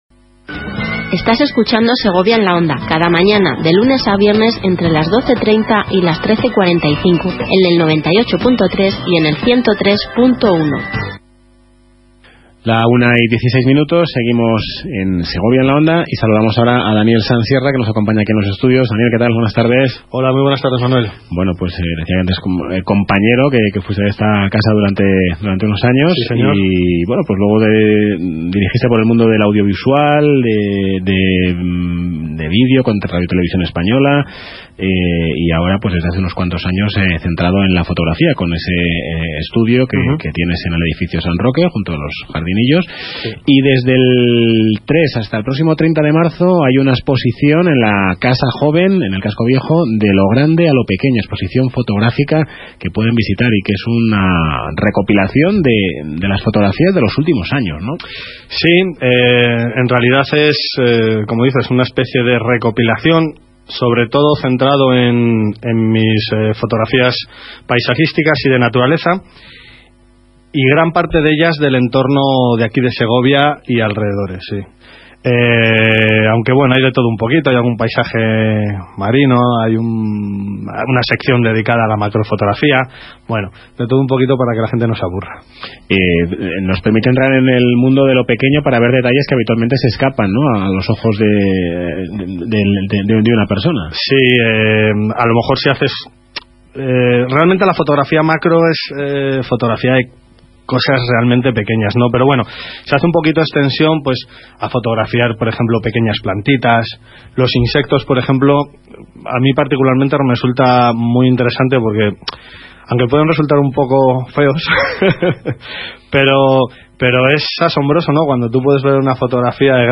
Onda-Cero-Entrevista-Exposición-Casa-Joven-marzo-2017.mp3